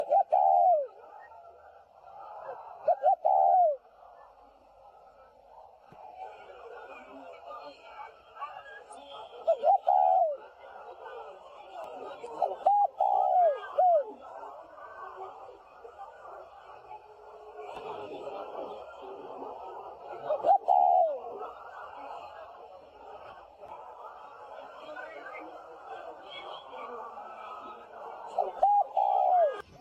野外珠颈斑鸠叫声拾取